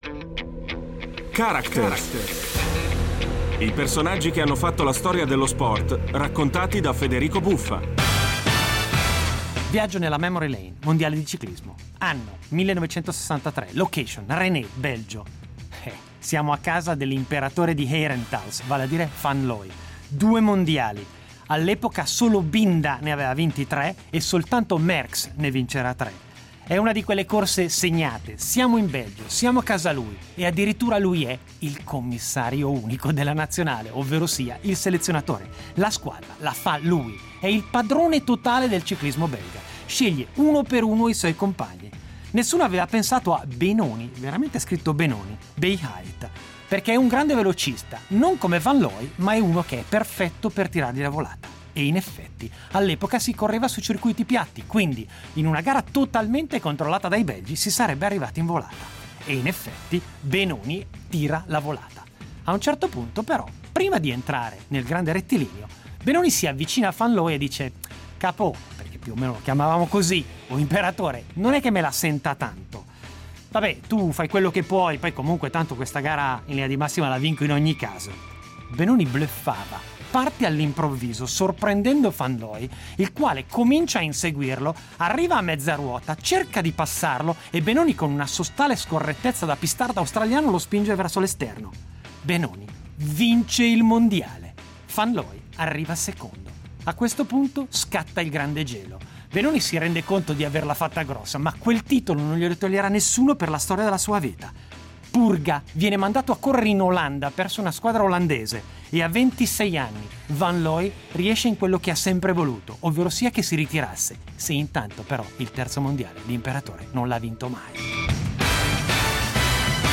Il campionato del mondo di ciclismo del 1963, disputatosi a Ronse (Belgio) e conclusosi con la vittoria del belga Benoni Beheyt ai danni del connazionale e capitano Rik Van Looy, raccontato da Federico Buffa.